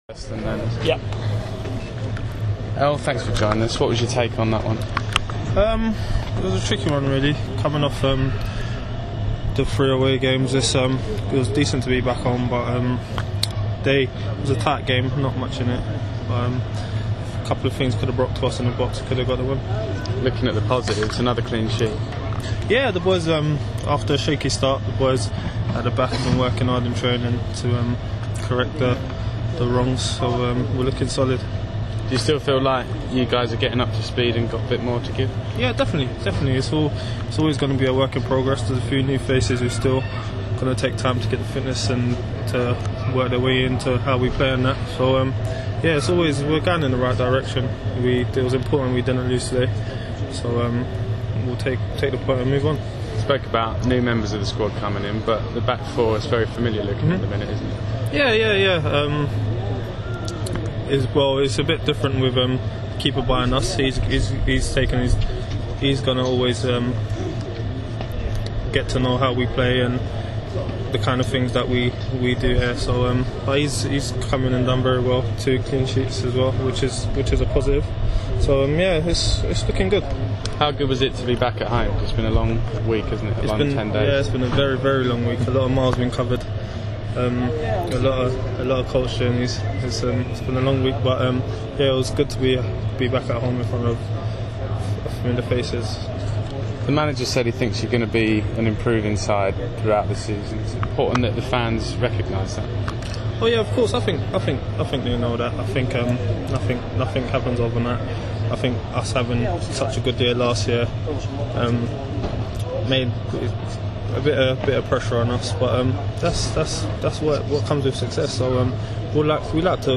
speaking after Orient's goalless draw against Walsall